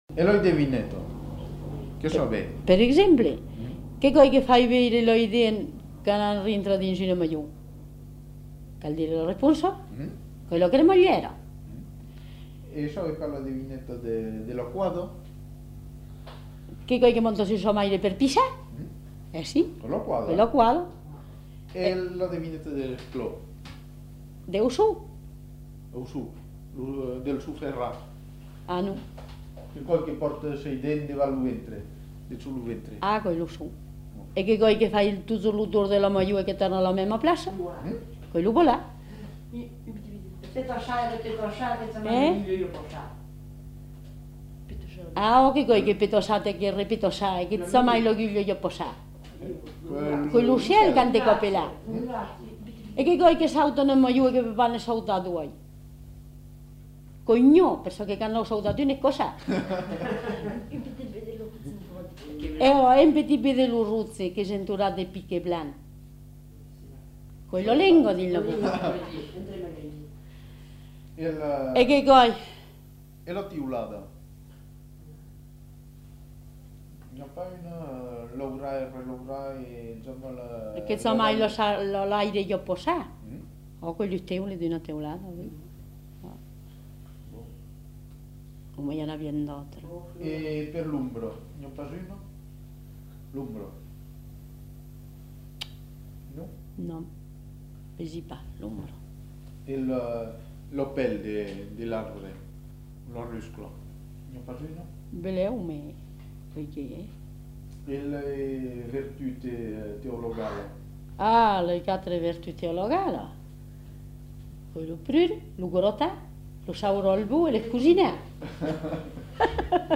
Devinettes
Aire culturelle : Périgord
Lieu : Castels
Effectif : 1
Type de voix : voix de femme
Production du son : récité
Classification : devinette-énigme